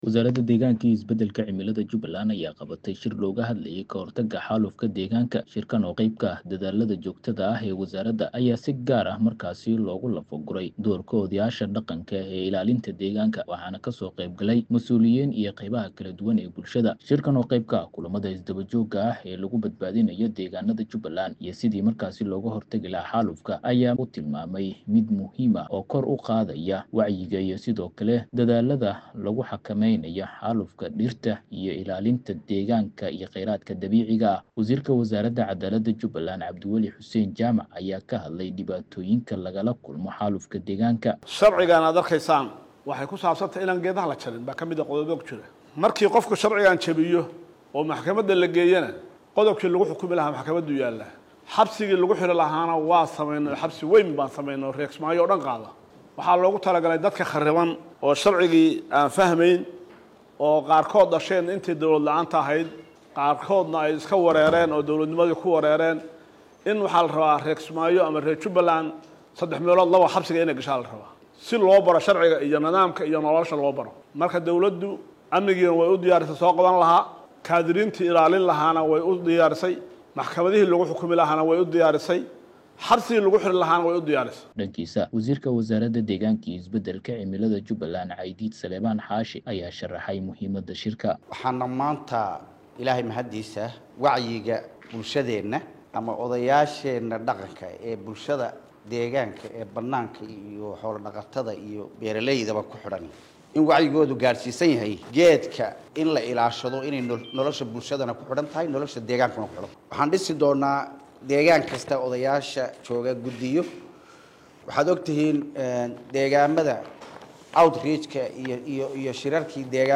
warbixintaan